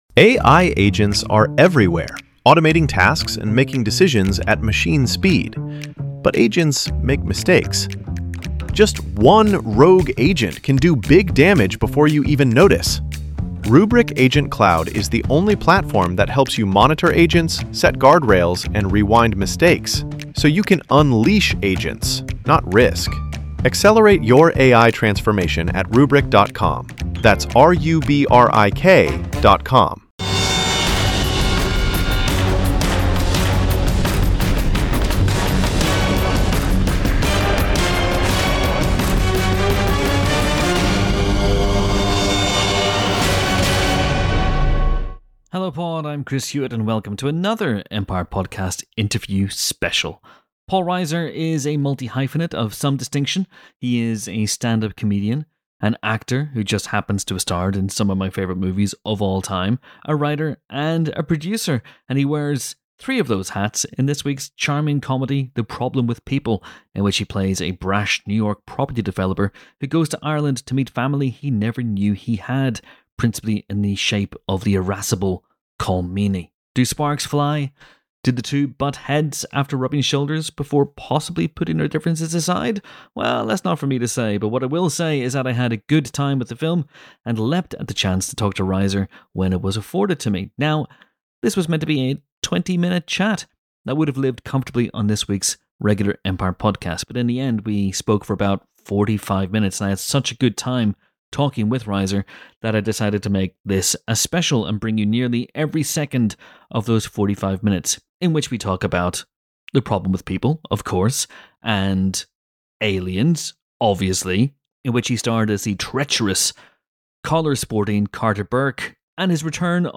Paul Reiser: An Empire Podcast Interview Special
paul_reiser_interview_special_mi_6a05bfc2_normal.mp3